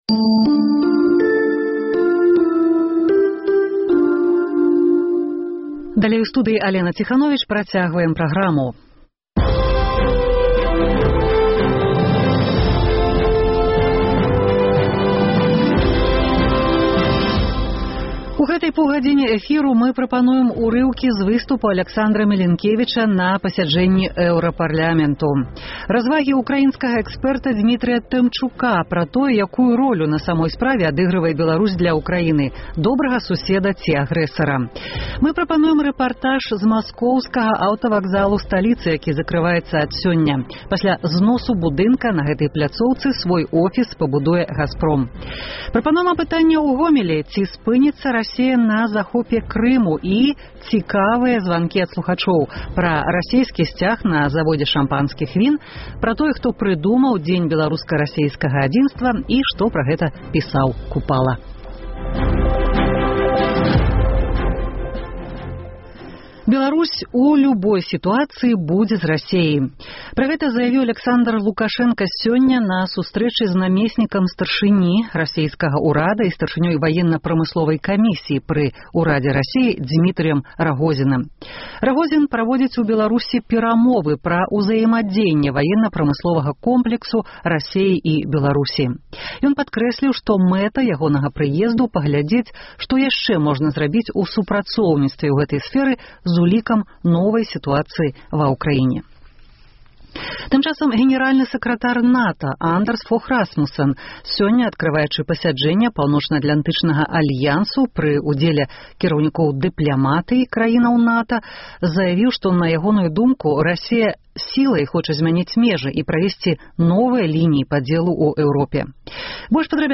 Рэпартаж з памежнай расейскай вёскі Кібіршчына: закрыты магазін, жыхары жывуць зборам мэталалому, глядзяць расейскае ТВ і гавораць амаль па-беларуску.